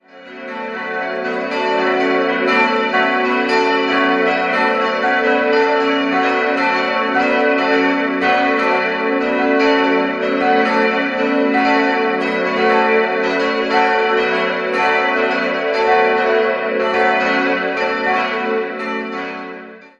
Die Innenausstattung stammt zum größten Teil ebenfalls aus der Barockzeit. 4-stimmiges ausgefülltes Fis-Moll-Geläute: fis'-a'-h'-cis'' Die Glocken wurden im Jahr 1963 von Friedrich Wilhelm Schilling in Heidelberg gegossen.